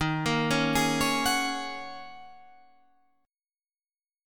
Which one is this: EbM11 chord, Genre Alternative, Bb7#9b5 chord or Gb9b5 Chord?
EbM11 chord